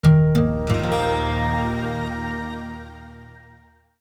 desktop-login.wav